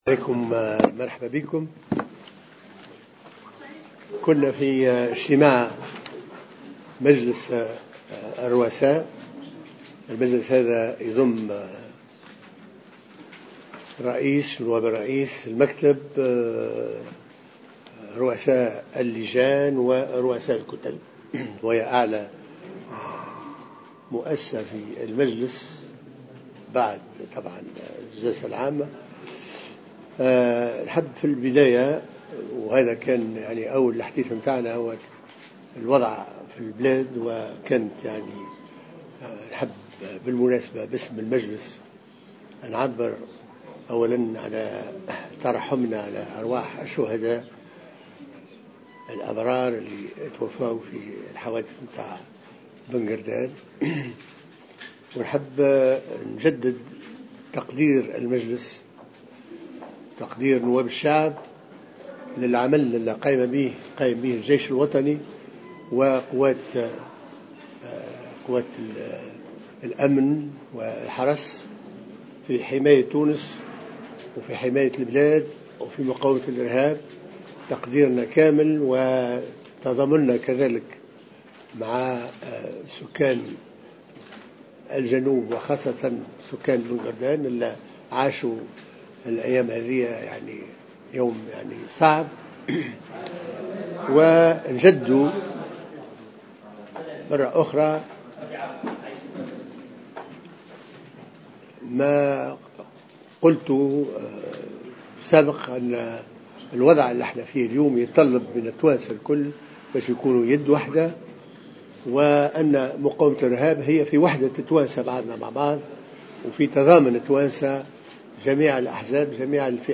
وقال في ندوة صحفية على اثر اجتماع لرؤساء اللجان التشريعية، إنه سيتم التسريع في نسق أعمال المجلس لتصبح أيام العمل 6 أيام عوضا عن 5 أيام الحالية.